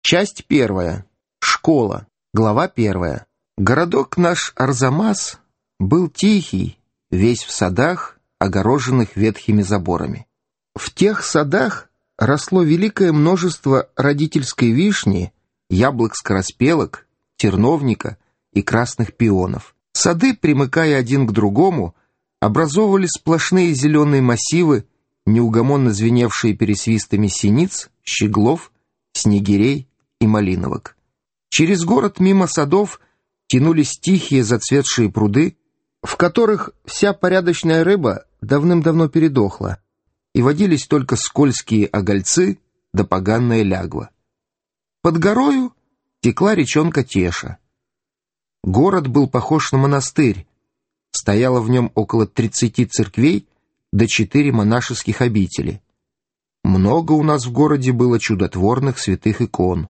Аудиокнига Школа | Библиотека аудиокниг